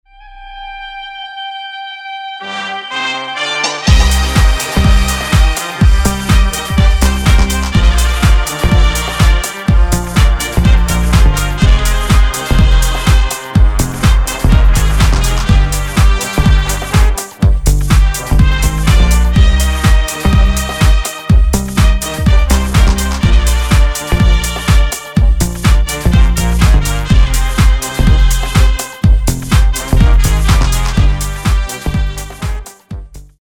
• Качество: 320, Stereo
deep house
без слов
nu disco
труба
Indie Dance
Trumpets